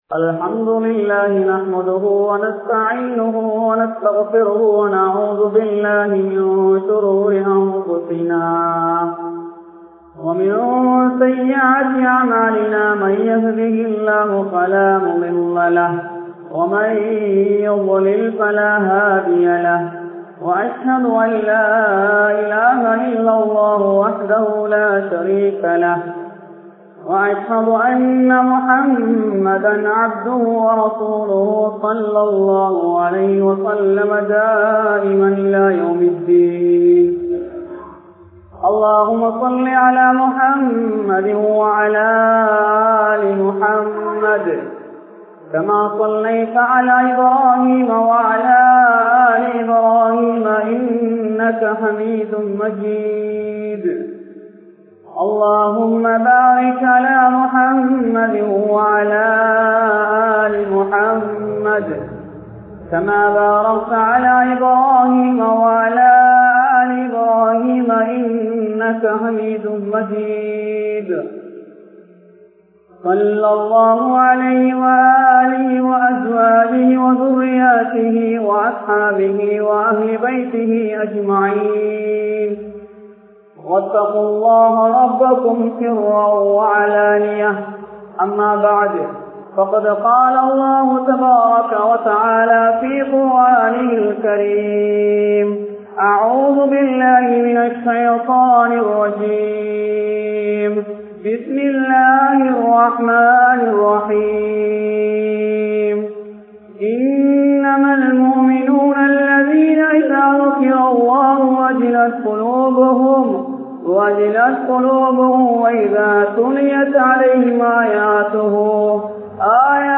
Allahvukkaha Vadiththa Kanneerin Perumathi (அல்லாஹ்வுக்காக வடித்த கண்ணீரின் பெறுமதி) | Audio Bayans | All Ceylon Muslim Youth Community | Addalaichenai
Mallawapitiya Jumua Masjidh